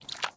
Minecraft Version Minecraft Version snapshot Latest Release | Latest Snapshot snapshot / assets / minecraft / sounds / mob / panda / eat11.ogg Compare With Compare With Latest Release | Latest Snapshot
eat11.ogg